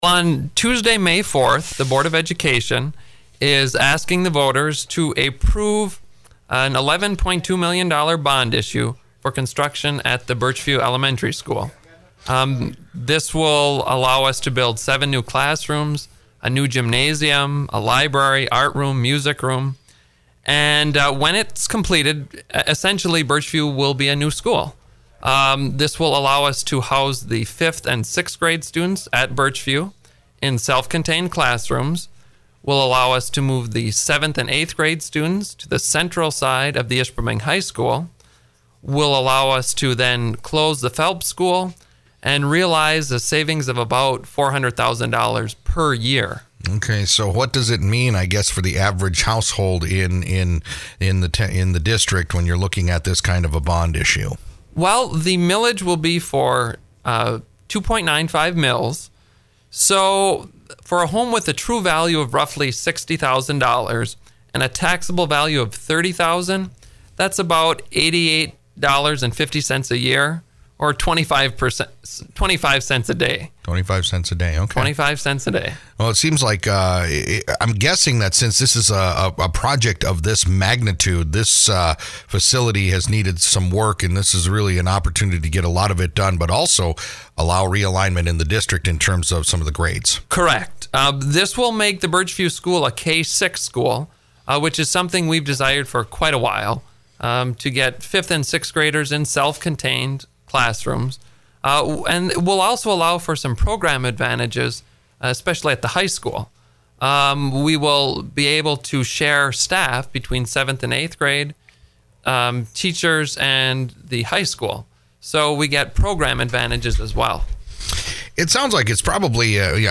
NewsOld Interviews Archive